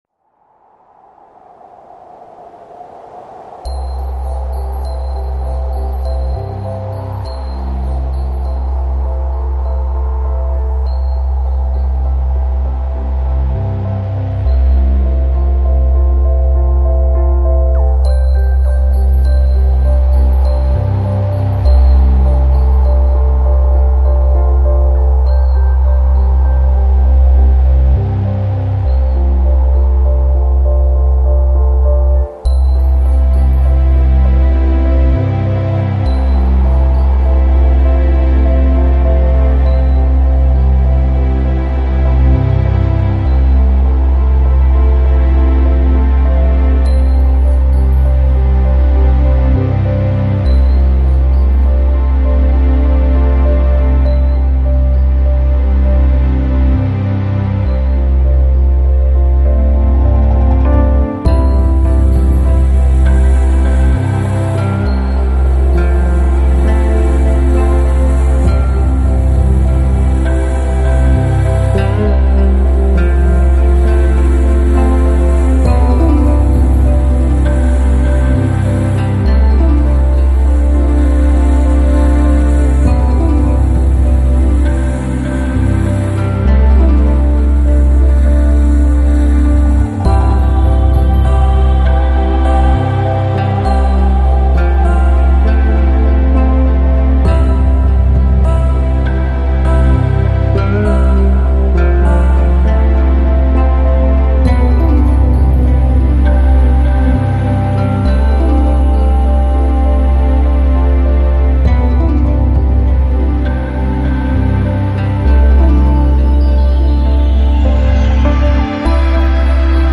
AAC Жанр: Lounge, Chill Out, Downtempo Продолжительность